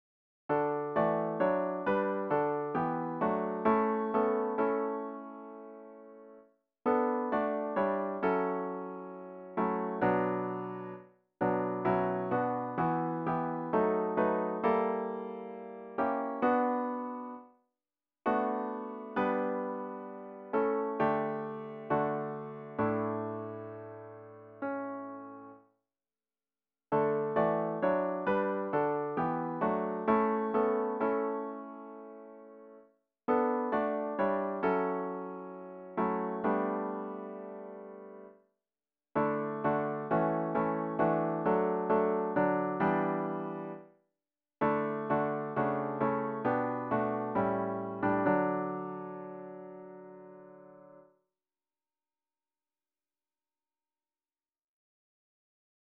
The hymn should be performed at a smooth♩= ca. 66.